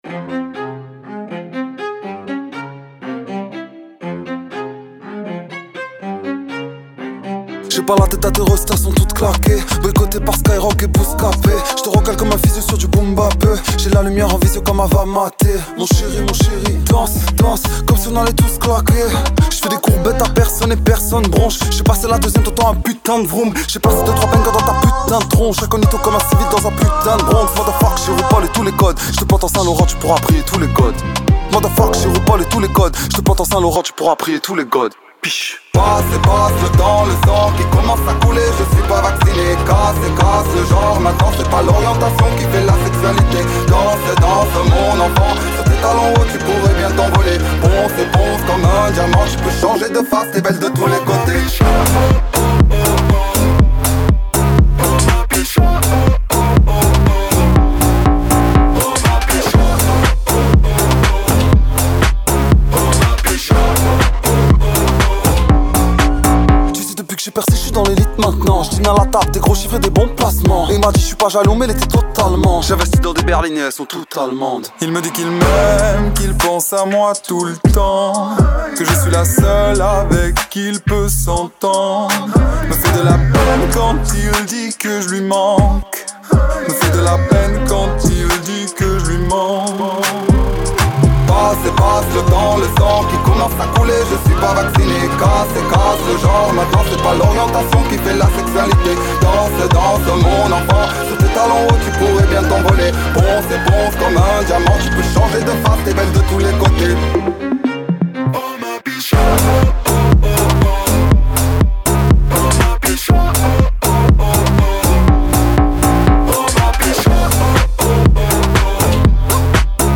Les Échos du Café Charbon - Interview